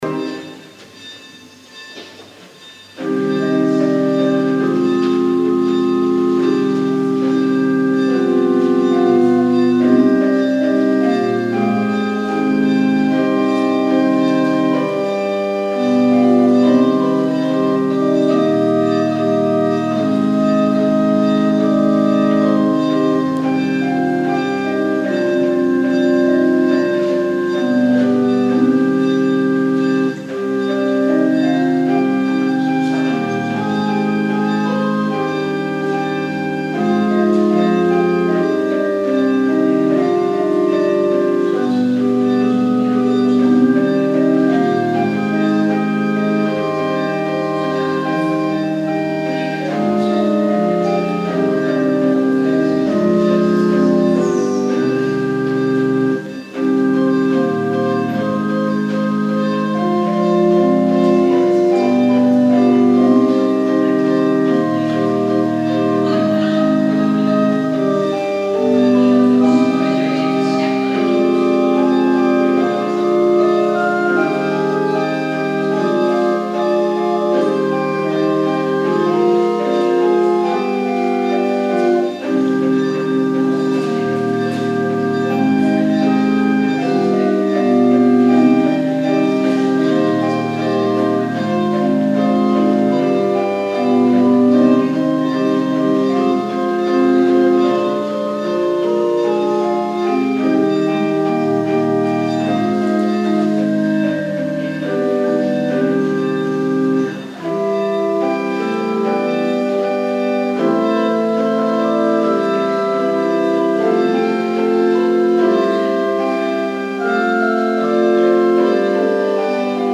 onderhoud orgel Dienst in Drimmelen Kerkdienst terug luisteren